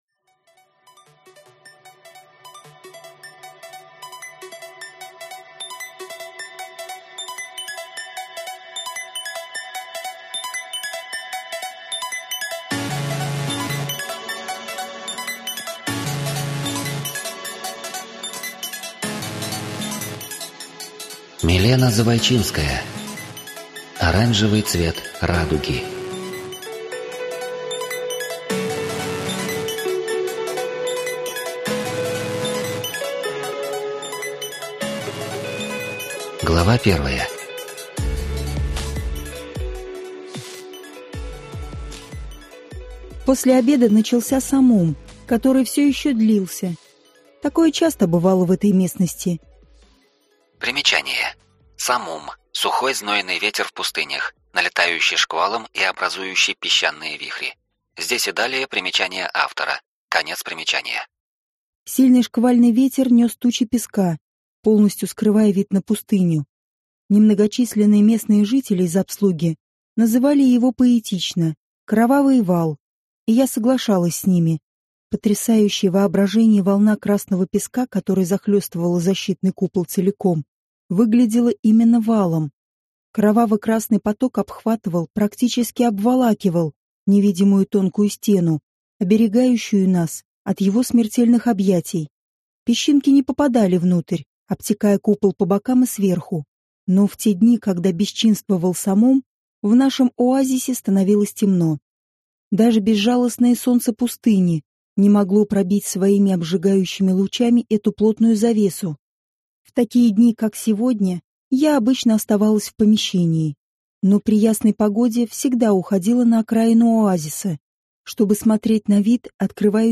Аудиокнига Оранжевый цвет радуги | Библиотека аудиокниг